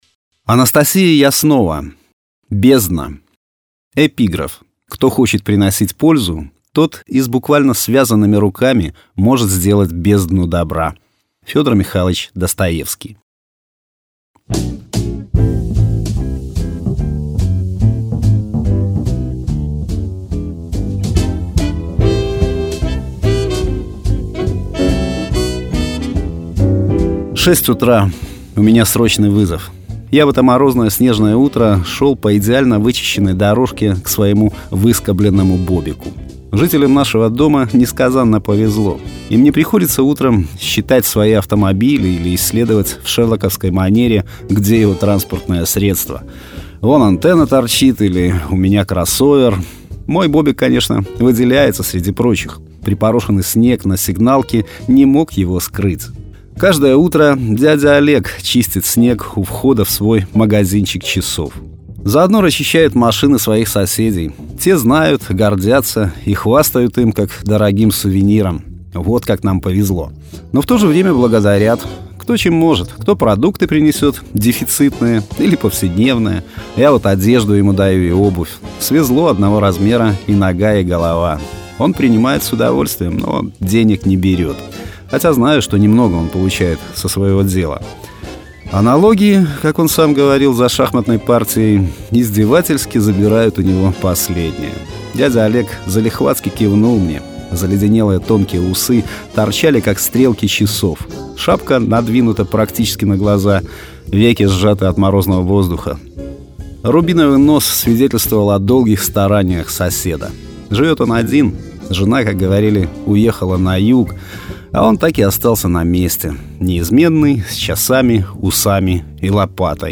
Жанр: Современная короткая проза
Качество: mp3, 256 kbps, 44100 kHz, Stereo